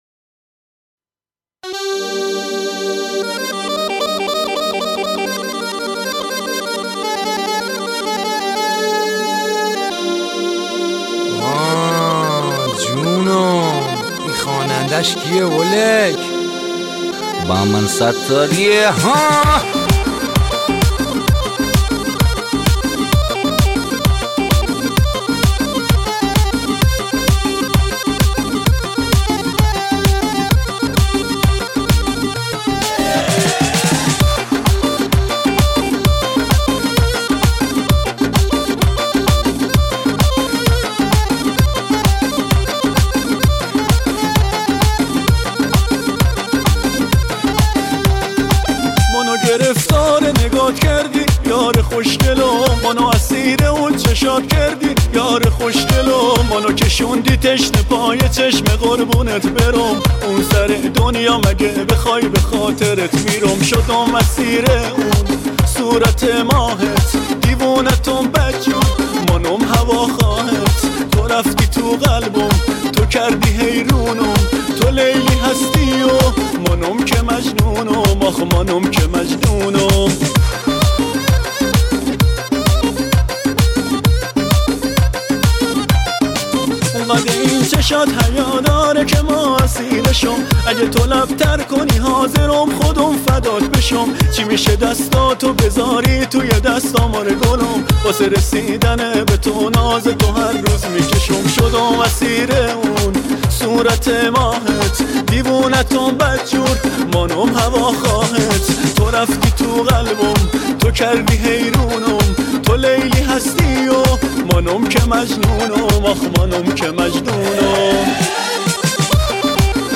اهنگ شیش و هشت بندری